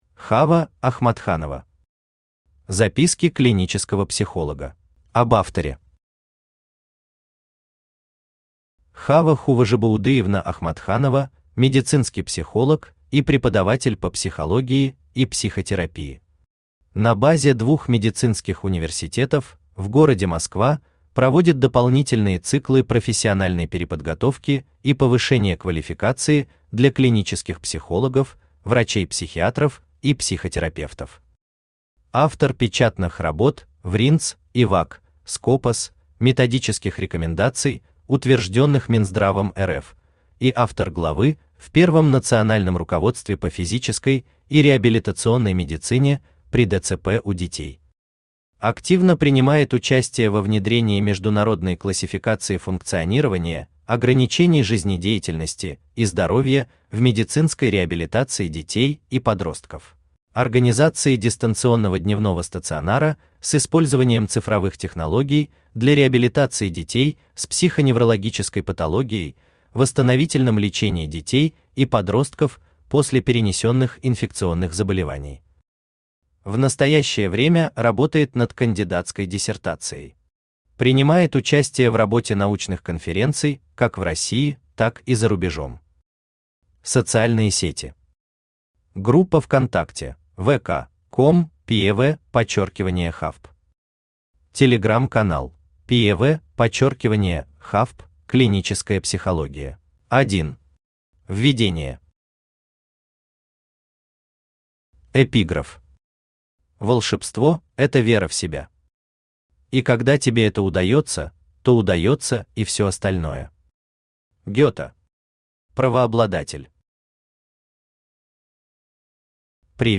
Аудиокнига Записки клинического психолога | Библиотека аудиокниг
Aудиокнига Записки клинического психолога Автор Хава Хуважибаудыевна Ахматханова Читает аудиокнигу Авточтец ЛитРес.